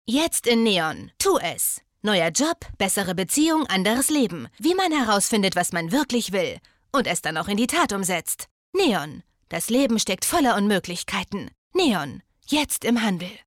hell, fein, zart
Jung (18-30), Mittel minus (25-45)
Commercial (Werbung)